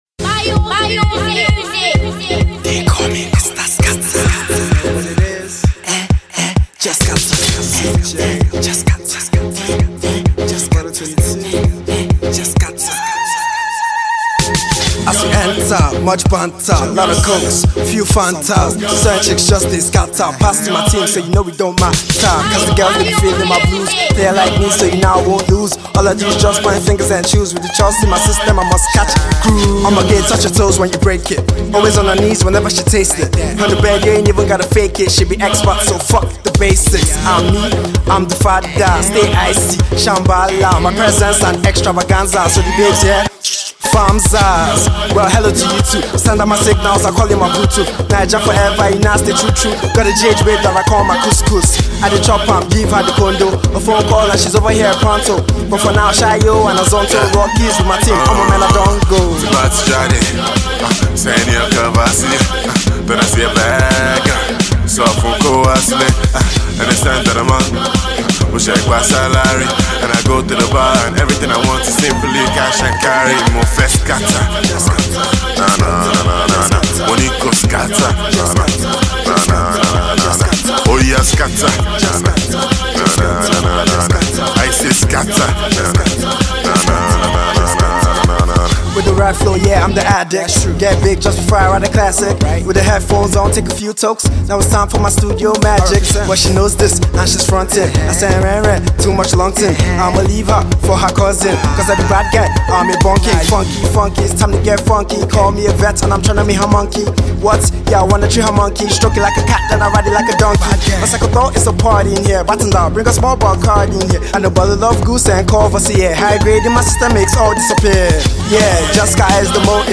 funky Afro-Hip-Hop joint